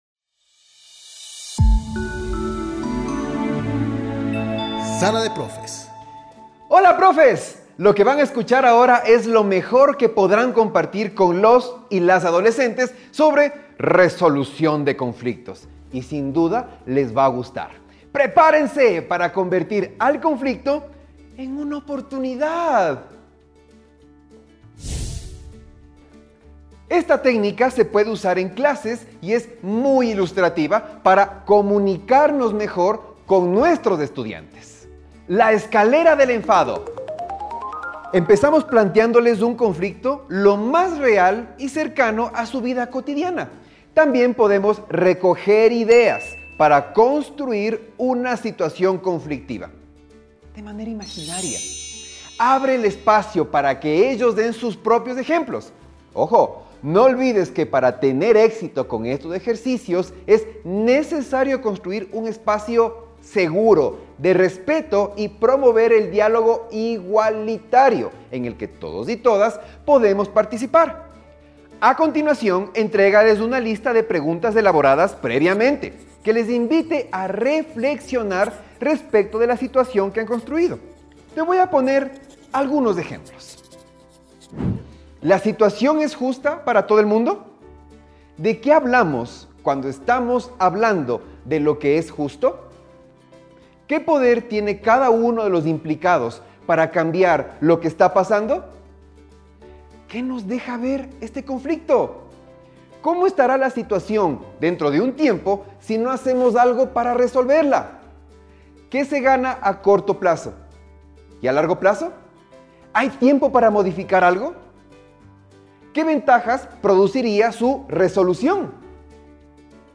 Descripción: La docente presenta una técnica a desarrollar, a partir de un ejercicio sencillo que invita a identificar herramientas para resolver conflictos, basándose en elementos de una cultura de paz.